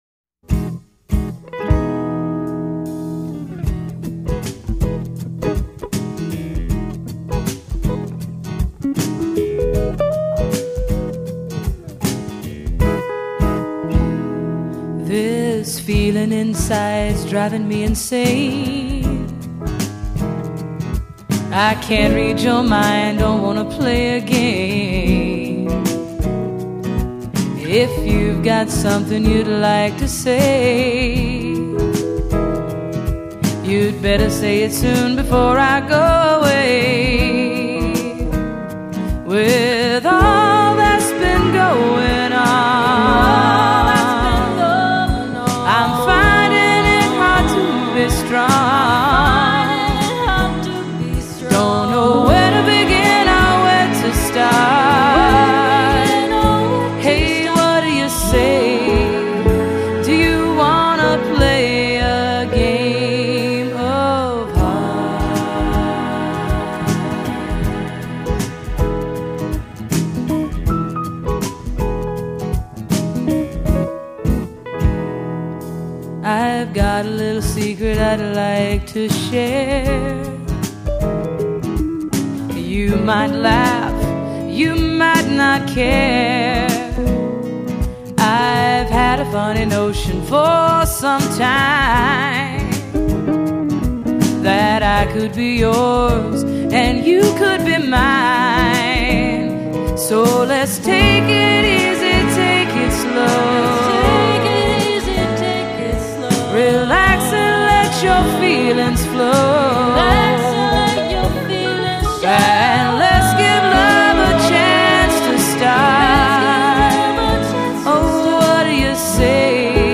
vocals, guitar
Drums
Sax
Guitar, keyboards